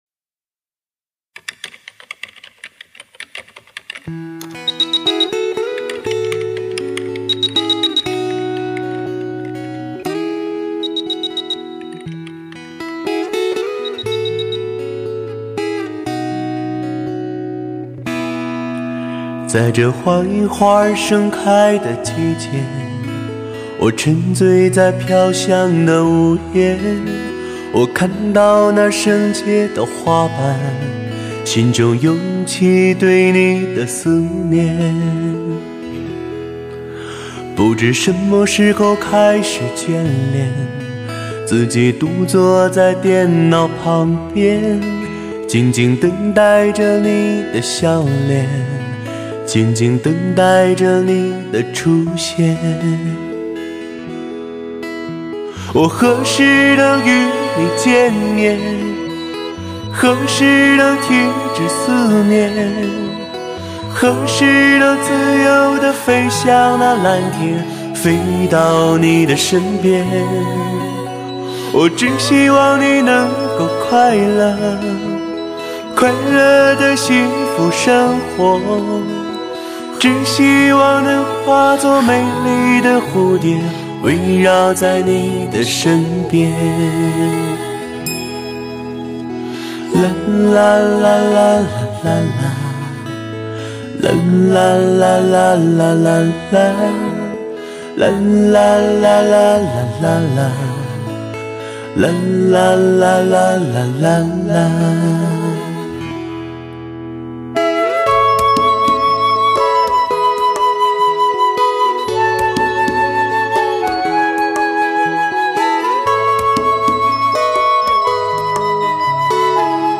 也展现了他那独特魅力的歌喉，简简单单的配乐起了点缀和烘托作用，